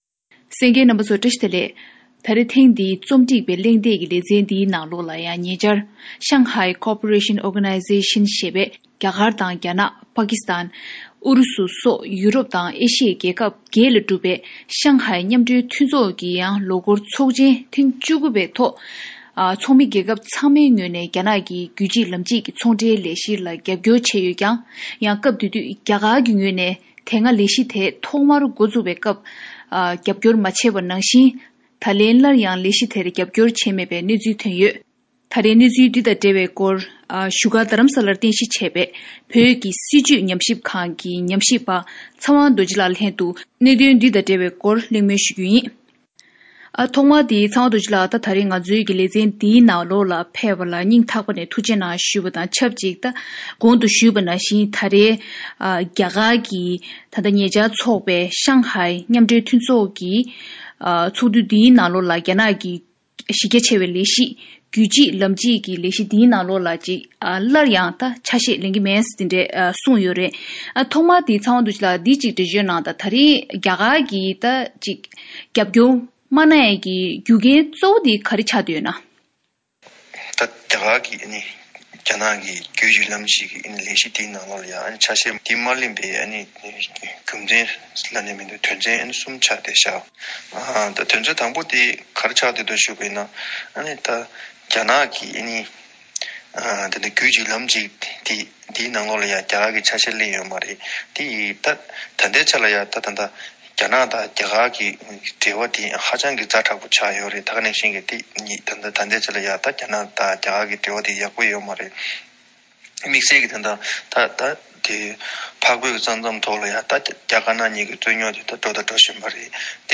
གླེང་མོལ་གནང་ཞུས་པར་གསན་རོགས་ཞུ།།